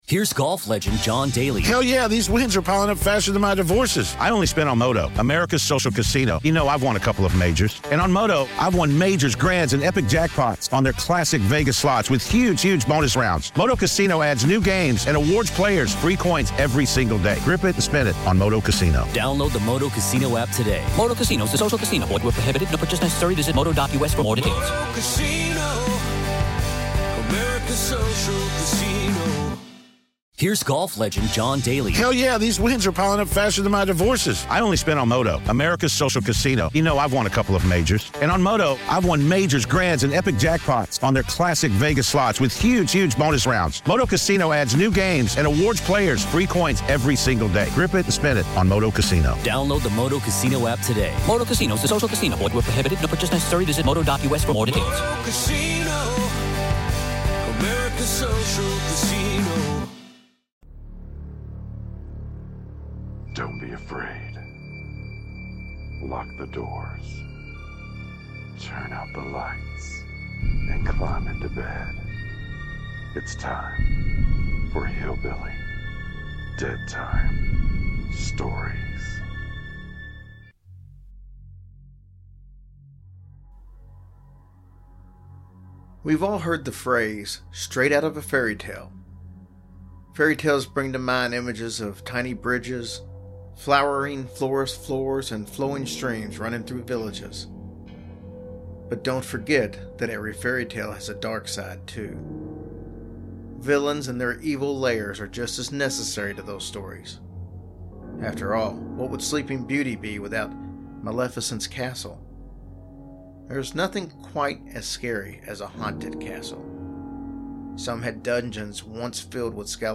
Introduction voice over